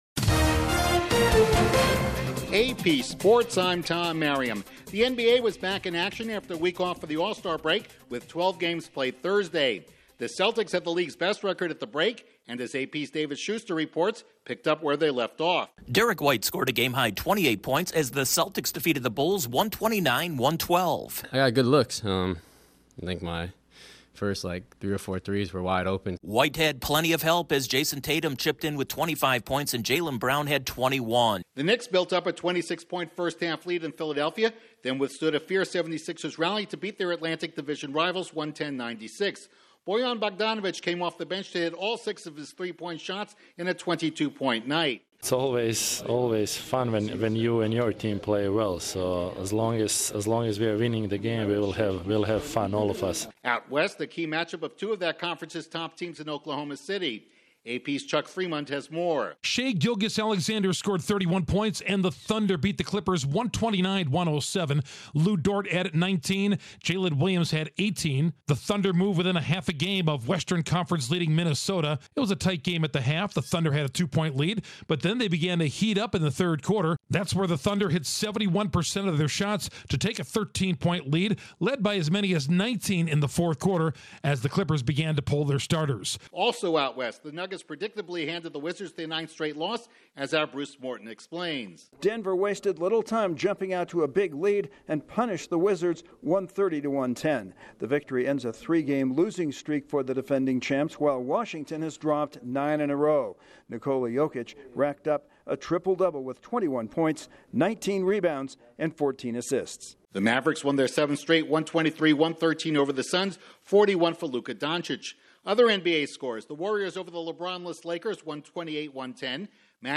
The NBA returns to action, number 3 Purdue wins but number 4 Arizona loses, the Rangers and Auston Matthews remain hot on the ice, and baseball's back on the diamond. Correspondent